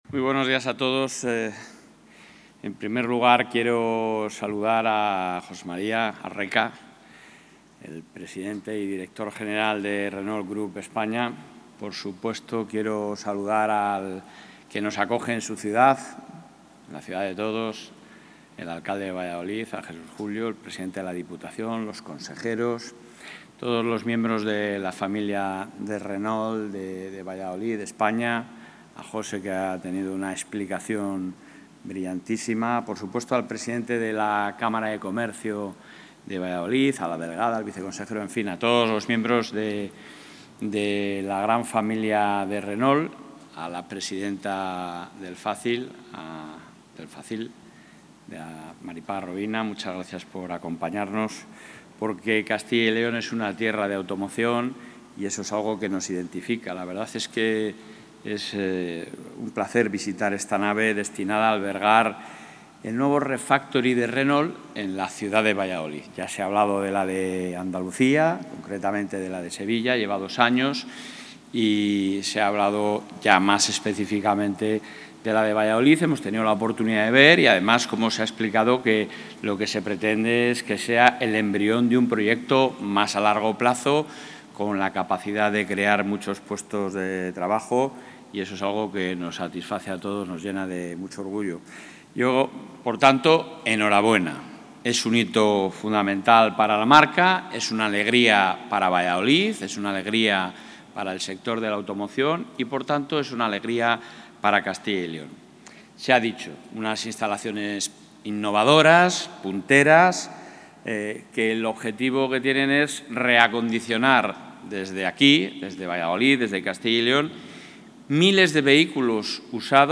Intervención del presidente de la Junta.
El presidente de la Junta de Castilla y León, Alfonso Fernández Mañueco, ha participado hoy en el acto de inauguración de la nueva Planta Refactory de Renault Group en Valladolid, un proyecto que inicia su actividad este año y que refuerza la posición estratégica de la Comunidad como referente nacional en el sector de la automoción.